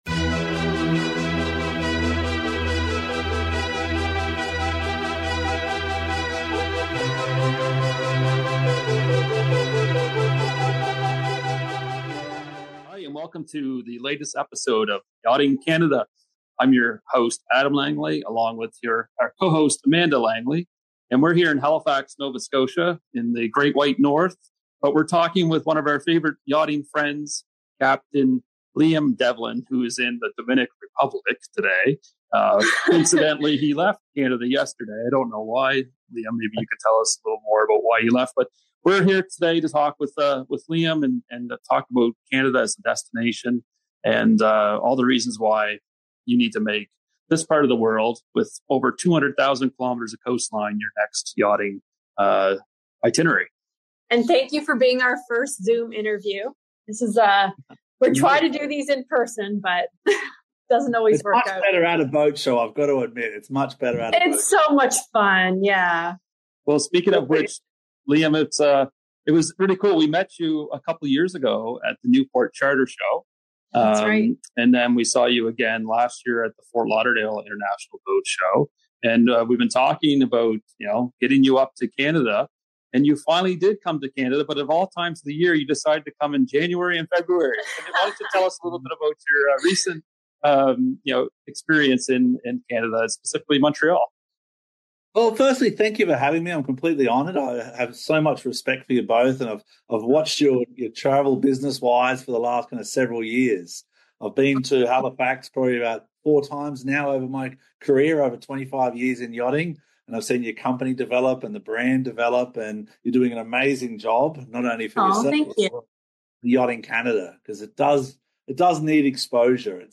🇨🇦 Filmed in Halifax, Nova Scotia, this episode takes you on an unforgettable journey through Canada’s breathtaking cruising grounds—from the Atlantic Provinces to the Great Lakes, Vancouver, and the wild beauty of Alaska.